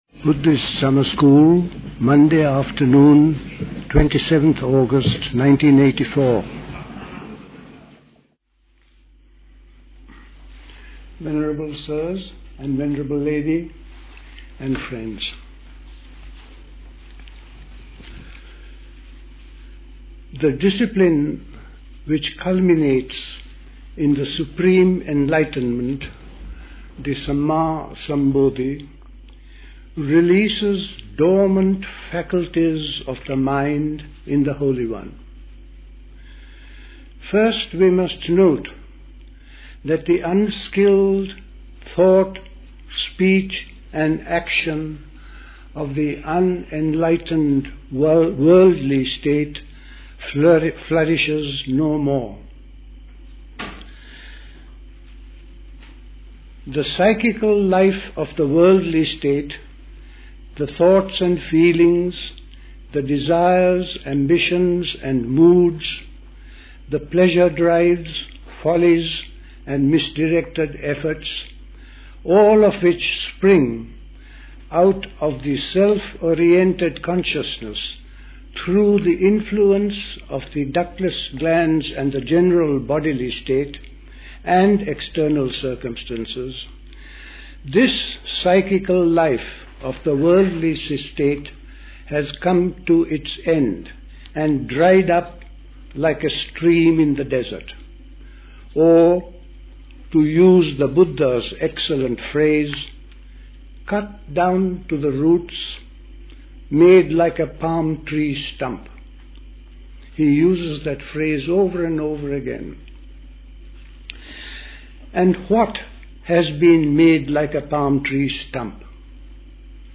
The Buddhist Society Summer School Talks